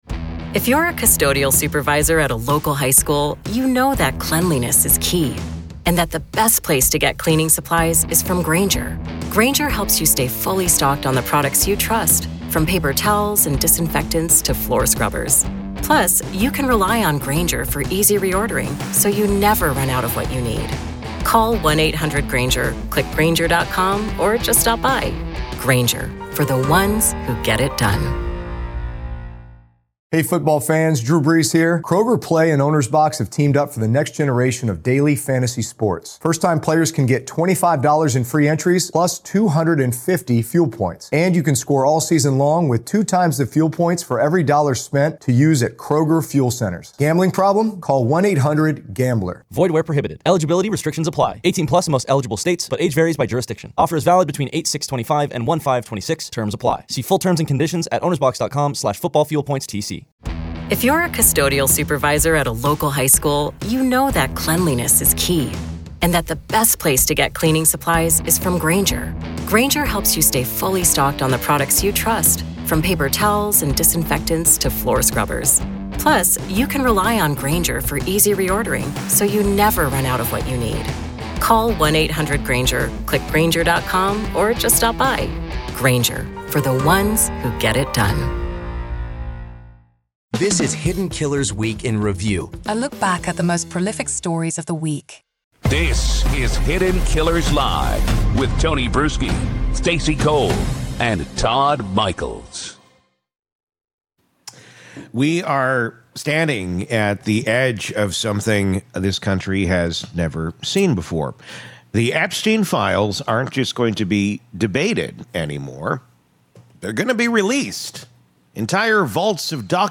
Hidden Killers Live! Daily True Crime News & Breakdowns